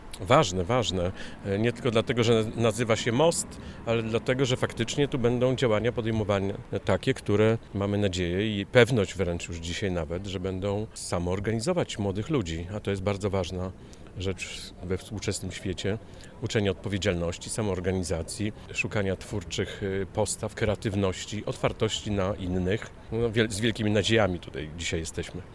Wiceprezydent podkreśla, że jest to ważne miejsce na mapie miasta.